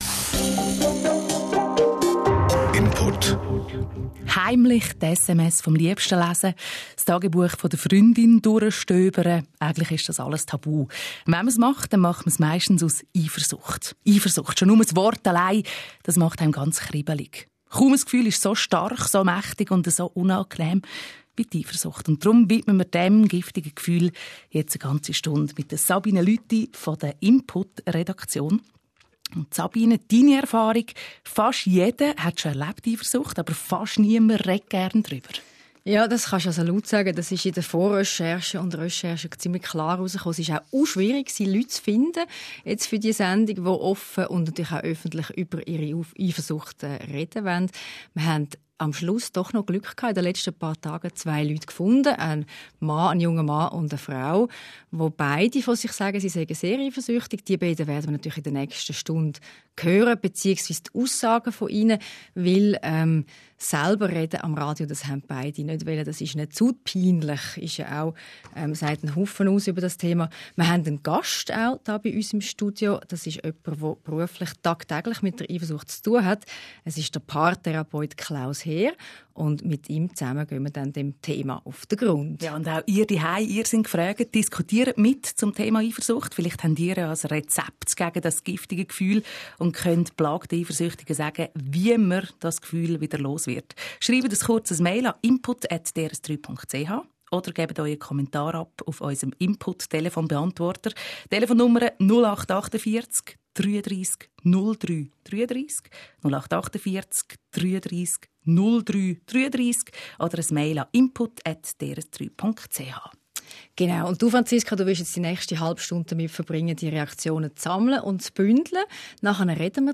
In der Hintergrundsendung Input kommen Eifersüchtige zu Wort